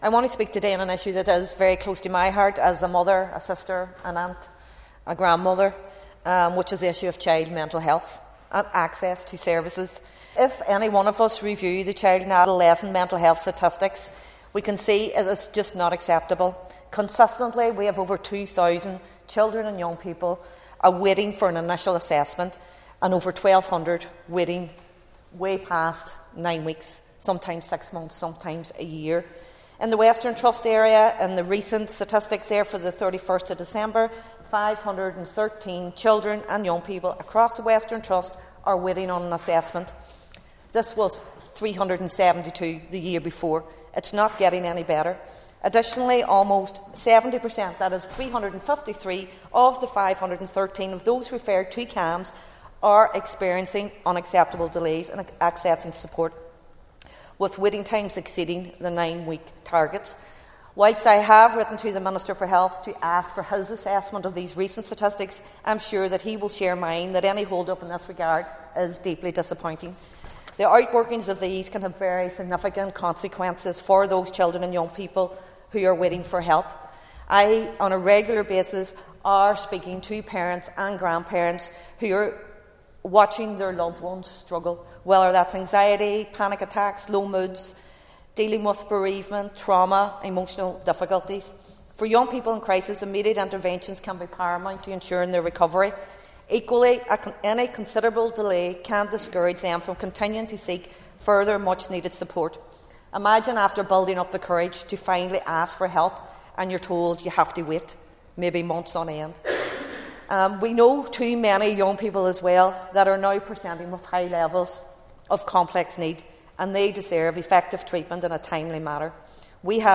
Foyle MLA Ciara Ferguson told members that the previously adopted Mental Health Strategy sets out ambitious plans for the future, but action is needed now to tackle what she says is a crisis………………